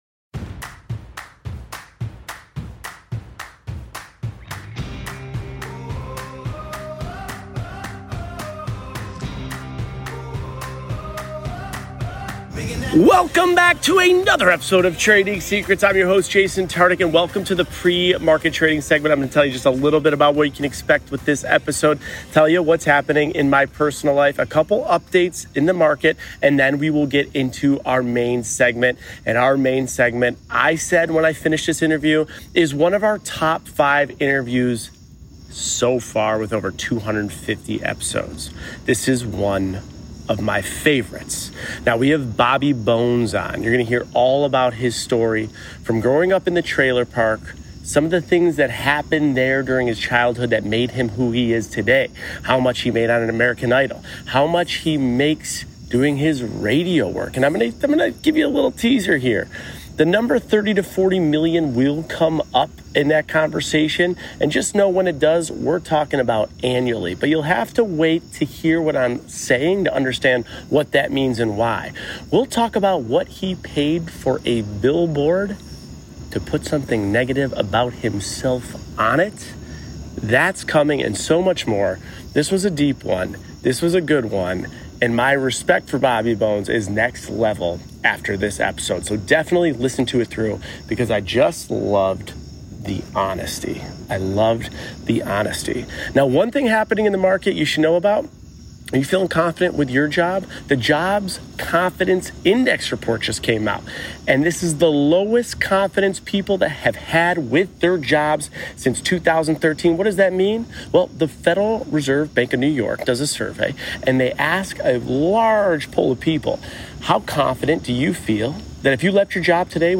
Guest: Bobby Bones